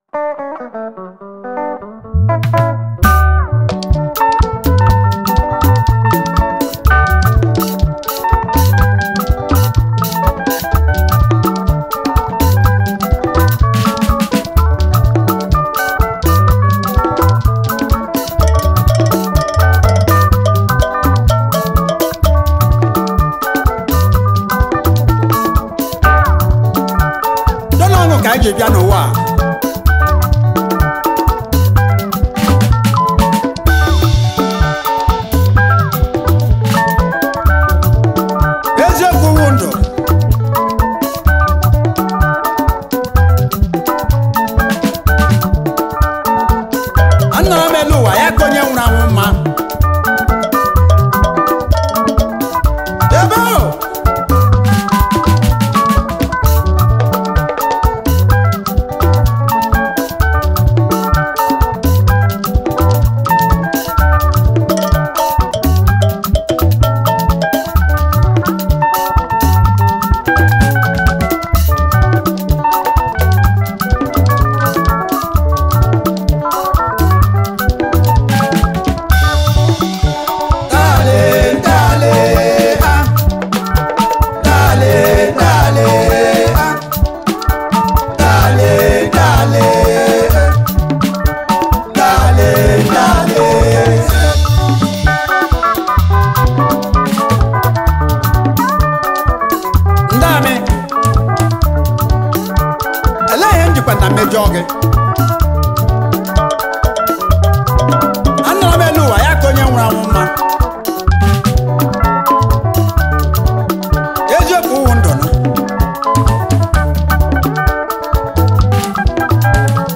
was a Nigerian orchestra high life band from Eastern Nigeria